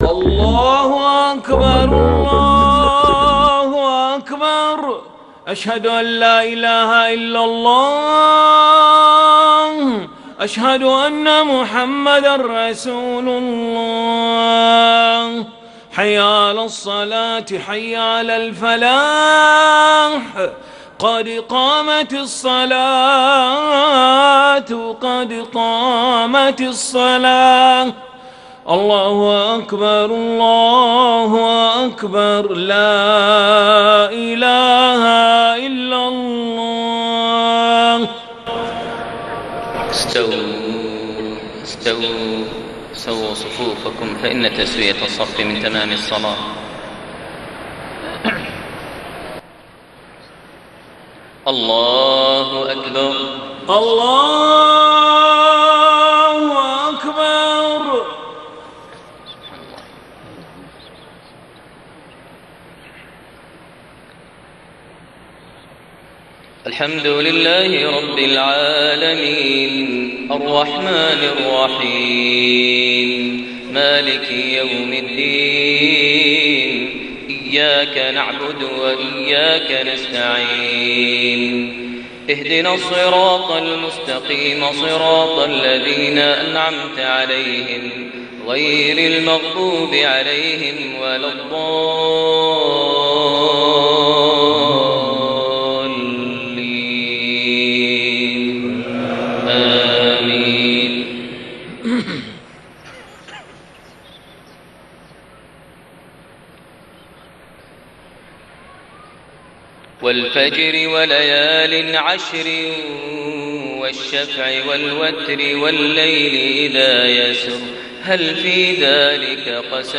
صلاة العشاء 3 شوال 1432هـ سورة الفجر > 1432 هـ > الفروض - تلاوات ماهر المعيقلي